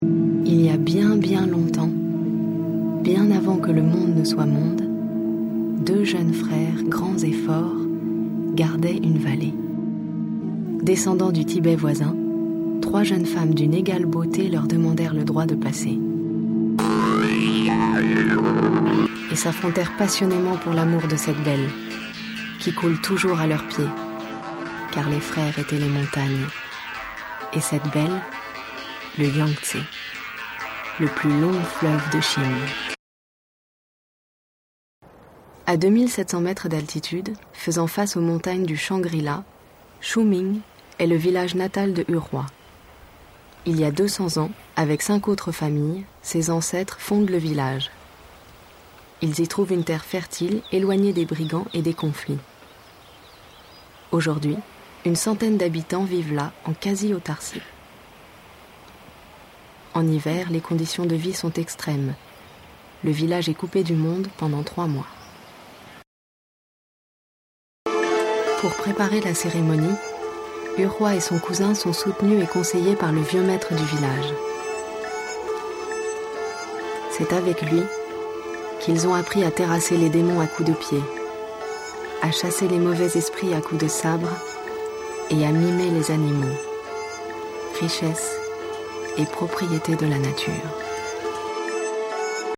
Voix-off documentaire "Les Naxis" pour ARTE